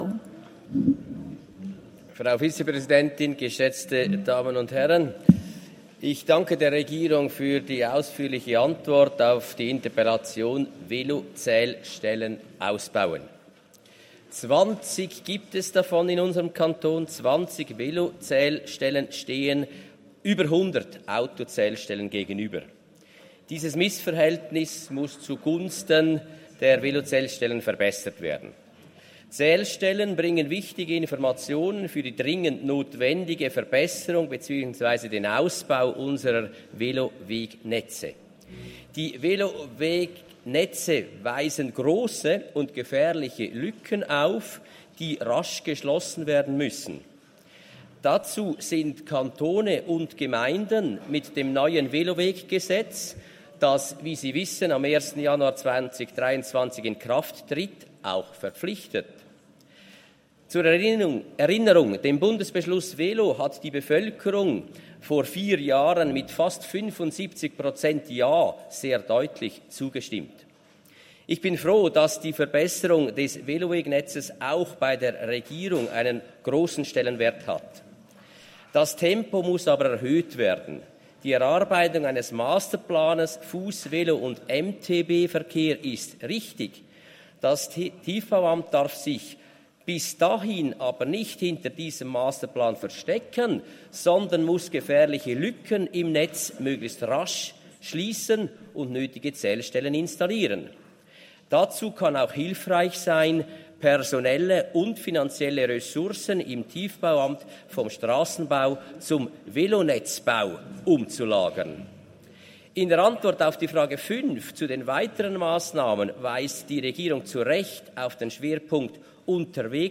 20.9.2022Wortmeldung
Session des Kantonsrates vom 19. bis 21. September 2022